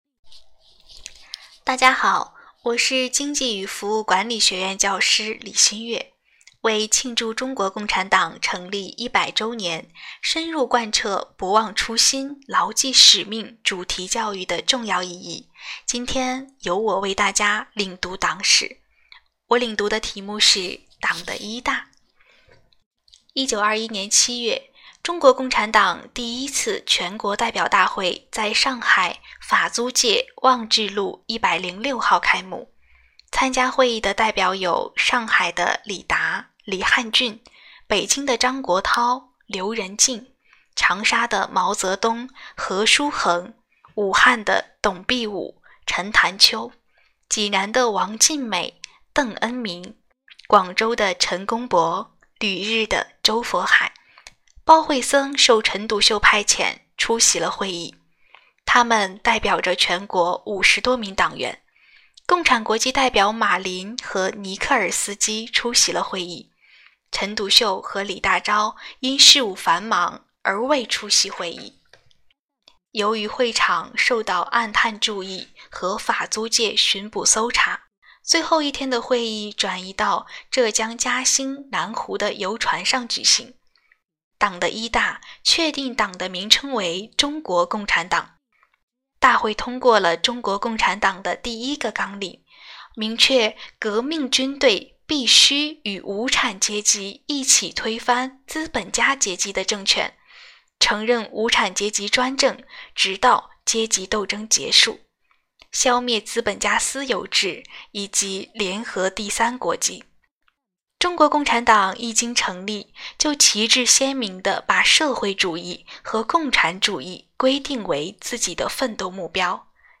“学党史，献职教，创辉煌” ——教师领读党史系列活动（一）